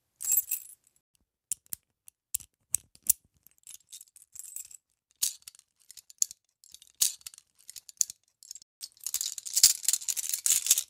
handcuffoff.wav